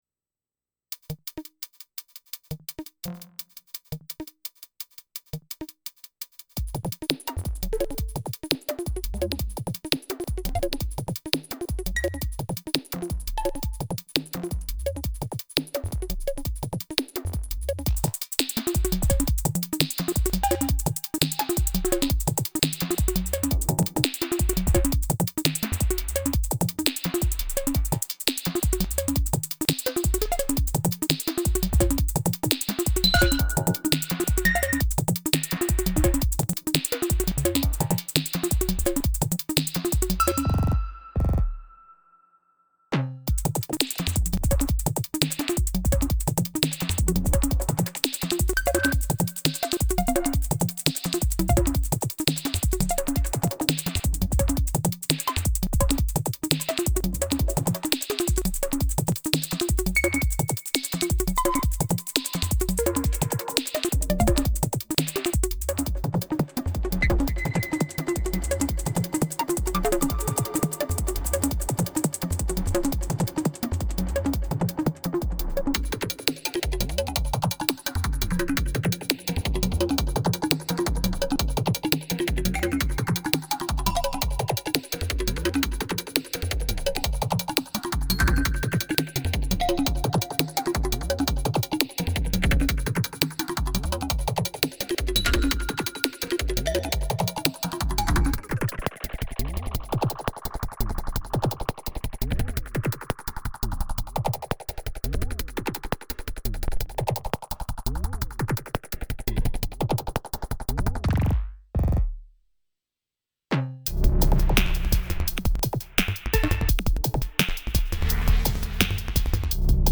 I “remixed” an old track and replaced all sounds with SY Toy. 170 bpm, lots of FX track and effects filtering.
Lots of short sounds with a lot definition :slight_smile: